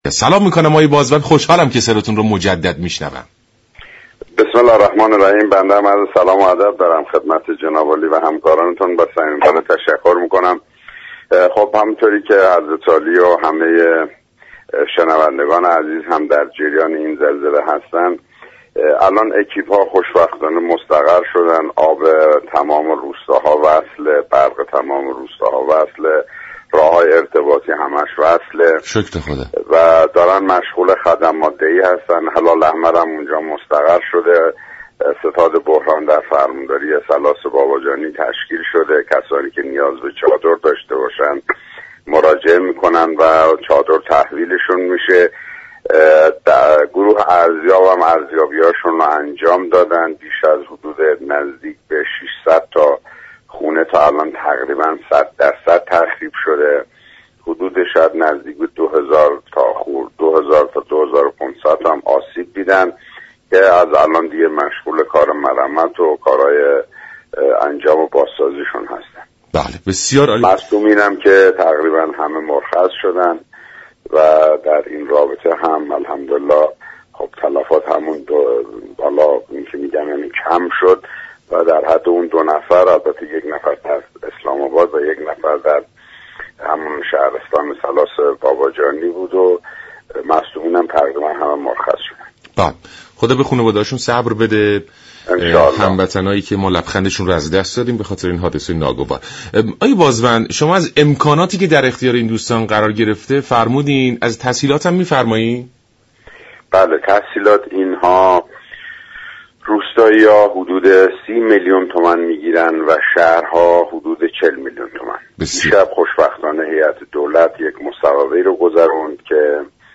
بازوند استاندار كرمانشاه در برنامه «سلام ایران» از زلزله كرمانشاه و آخرین گزارشات مربوط به این حادثه خبر داد.